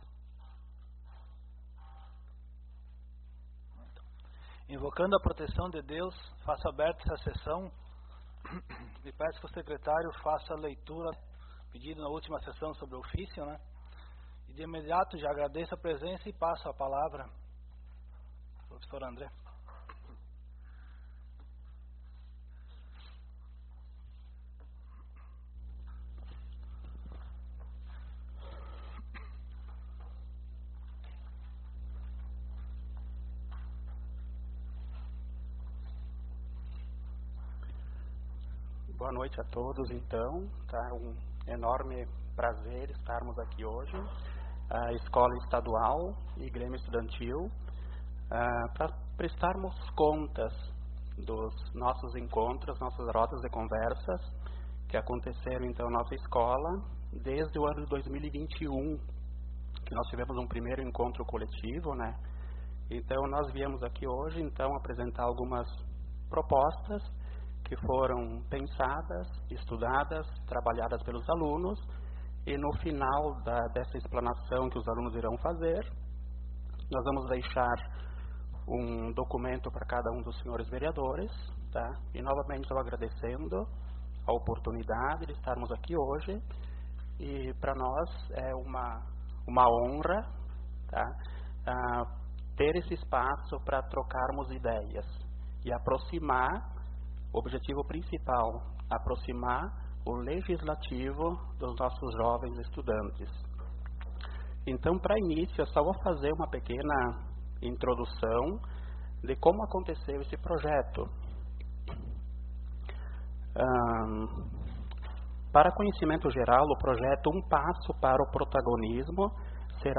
Publicação: 25/09/2023 às 00:00 Abertura: 25/09/2023 às 00:00 Ano base: 1969 Número: Palavras-chave: Boletim Informativo da Sessão Ordinária realizada no dia 25 de setembro de 2023, sob a Presidência do Sr. Vereador Rodrigo Antônio Vieceli.